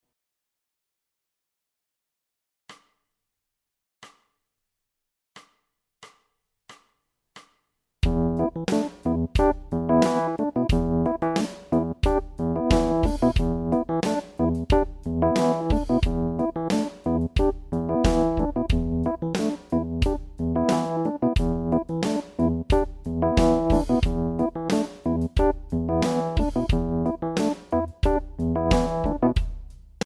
Funk `n` Soul Riff 4